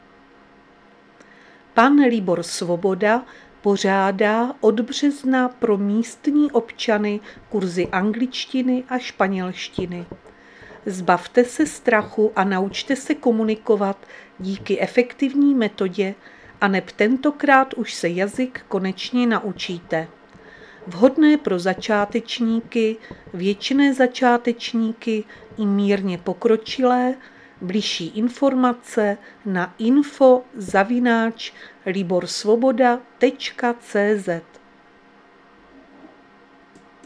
Záznam hlášení místního rozhlasu 10.3.2026